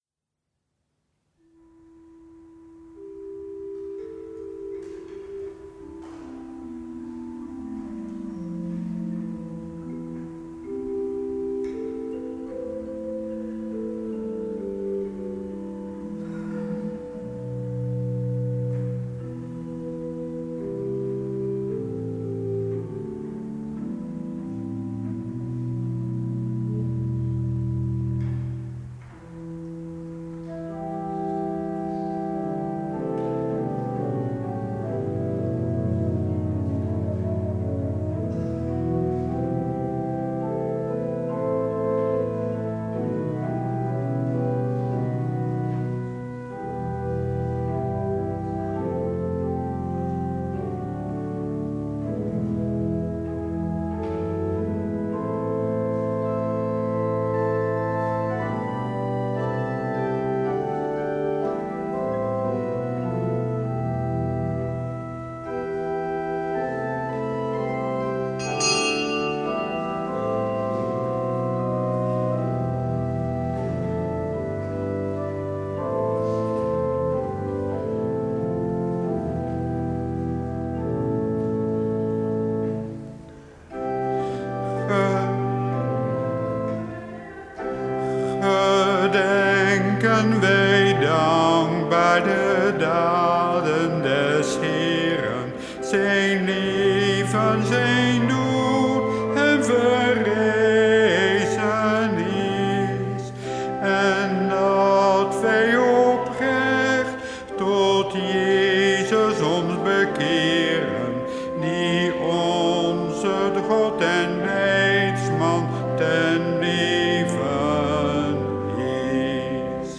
Eucharistieviering beluisteren vanuit de St. Jozefkerk te Wassenaar (MP3)